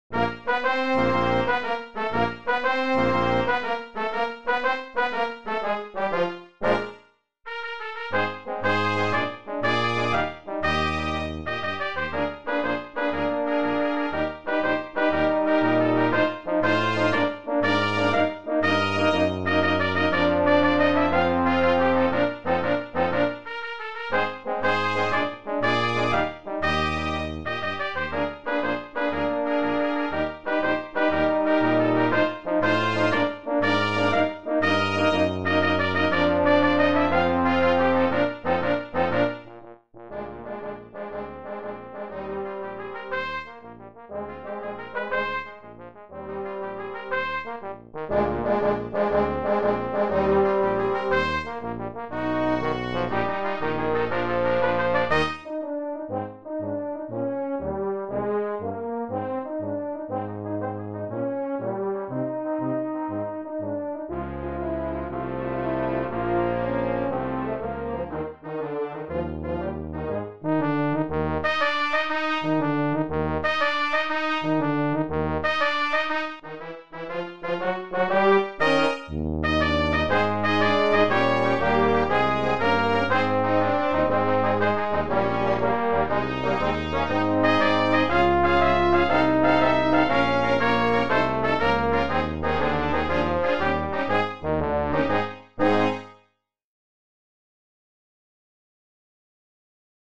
Brass Quintet
Marches included in this collection: